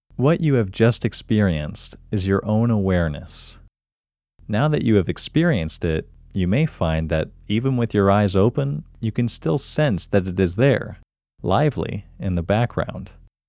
QUIETNESS Male English 18
The-Quietness-Technique-Male-English-18.mp3